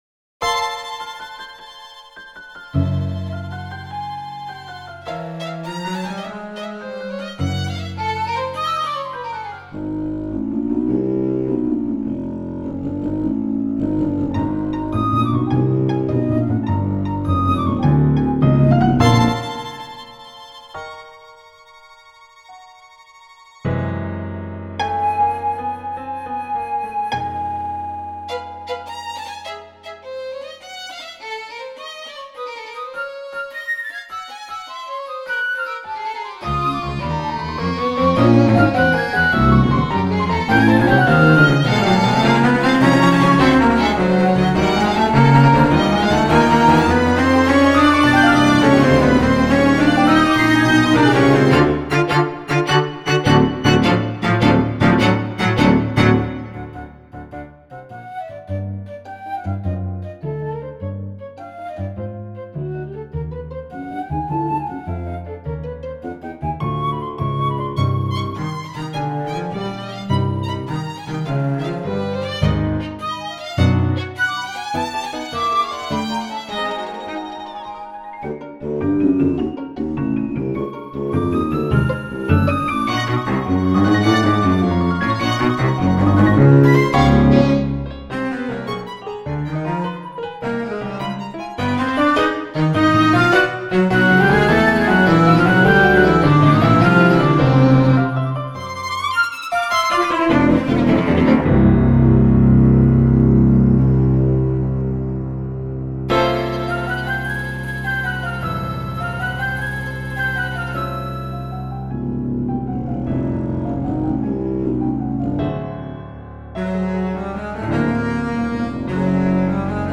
Daunting Steps - Quintet for Piano, Flute, Contrabassoon, Violin, and Cello - 2025 Halloween Submission